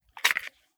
9mm Micro Pistol - Dropping Magazine 001.wav